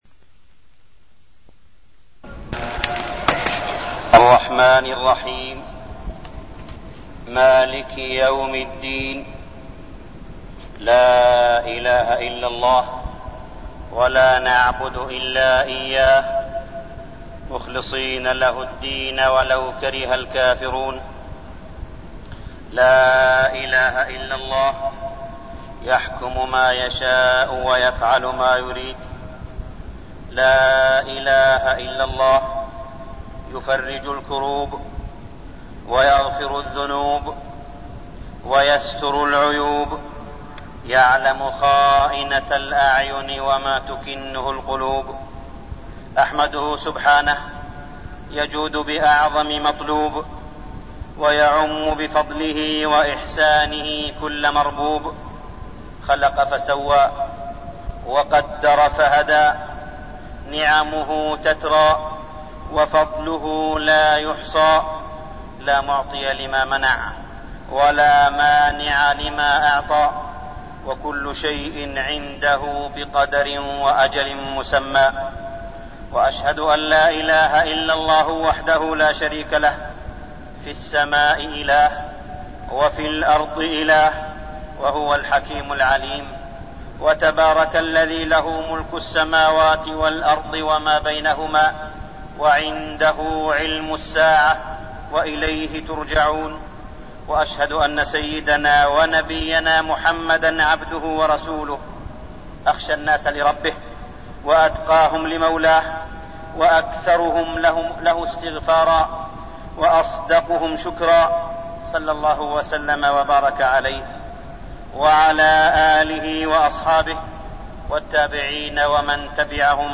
تاريخ النشر ٩ ربيع الأول ١٤١١ هـ المكان: المسجد الحرام الشيخ: معالي الشيخ أ.د. صالح بن عبدالله بن حميد معالي الشيخ أ.د. صالح بن عبدالله بن حميد الحث على التمسك بالقرآن والسنة